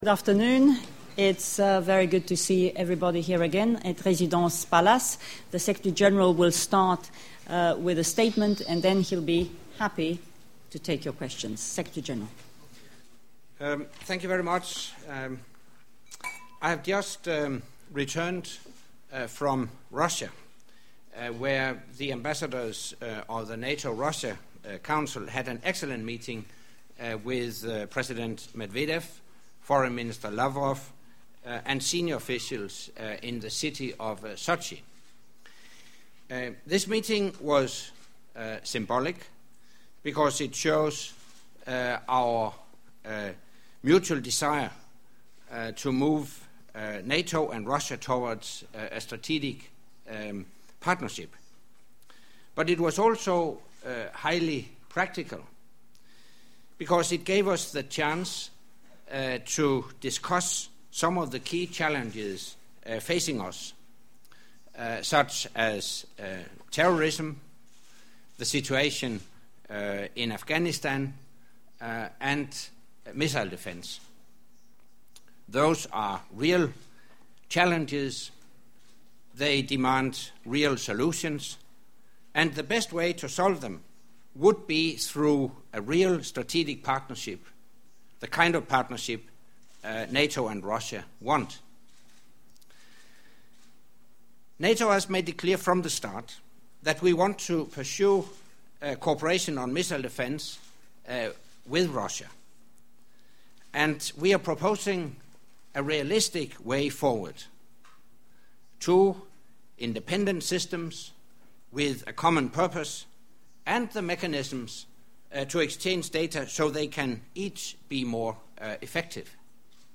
Щомісячний брифінг для преси - Генерального секретаря НАТО Андерса Фога Расмуссена
У середу 6 липня Генеральний секретар НАТО Андерс Фог Расмуссен виступив зі своїм щомісячним брифінгом для журналістів.
Monthly press briefing by NATO Secretary General Anders Fogh Rasmussen at Residence Palace, Brussels